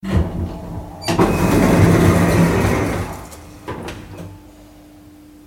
دانلود صدای باز شدن در آسانسور از ساعد نیوز با لینک مستقیم و کیفیت بالا
جلوه های صوتی